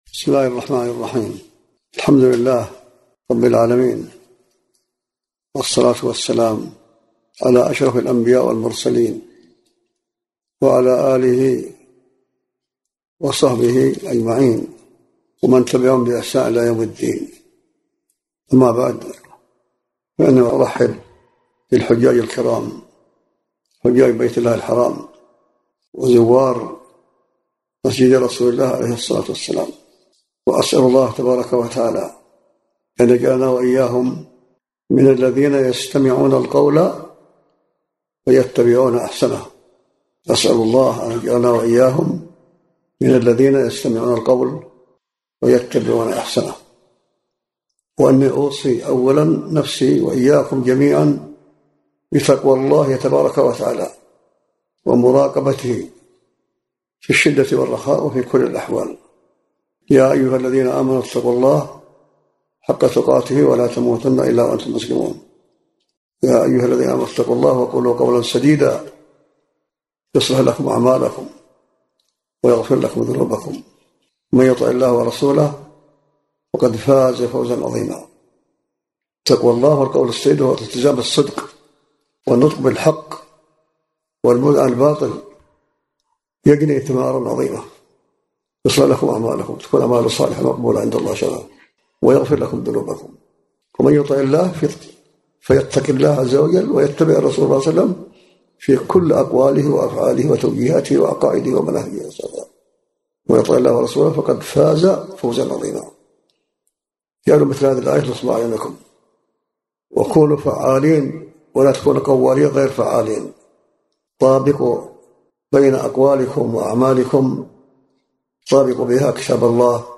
كلمة مختصرة في صفة حجة النبي -صلى الله عليه وسلم- مع الوصية بالبعد عن الفرقة وأسبابها، ألقاها الشيخ على مجموعة من الحجاج والزوار.